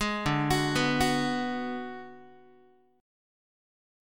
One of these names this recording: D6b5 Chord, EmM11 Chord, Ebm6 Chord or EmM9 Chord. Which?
D6b5 Chord